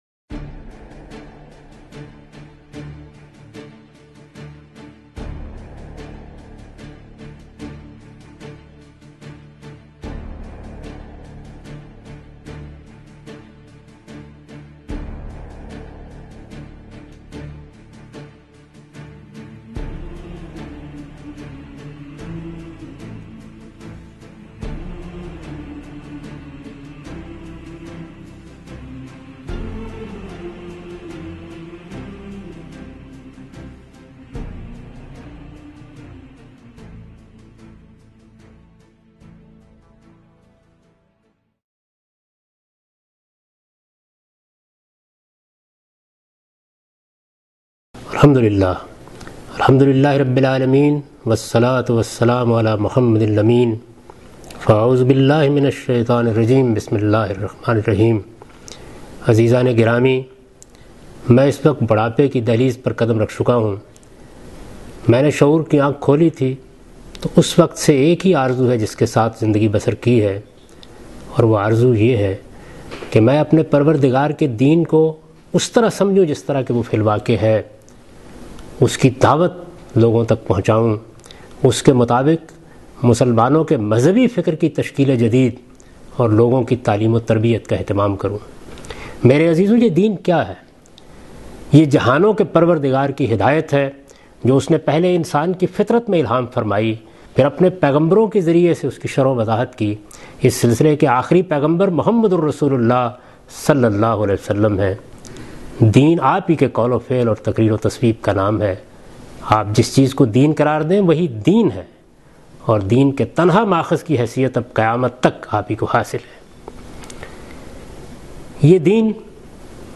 اس پروگرام میں جاوید احمد غامدی اسلام کی دعوت دے رہے ہیں